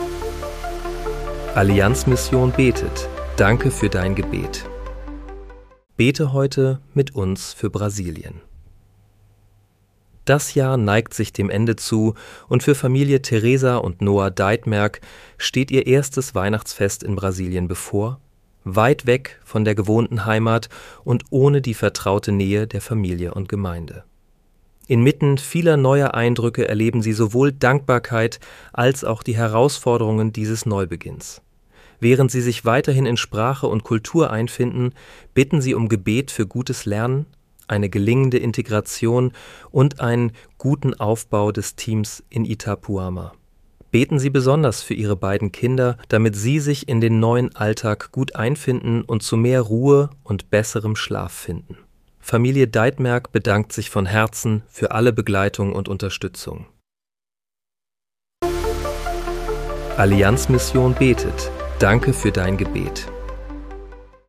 Bete am 22. Dezember 2025 mit uns für Brasilien. (KI-generiert mit